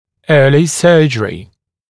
[‘ɜːlɪ ‘sɜːʤ(ə)rɪ][‘ё:ли ‘сё:дж(э)ри]хирургическое лечение в раннем возрасте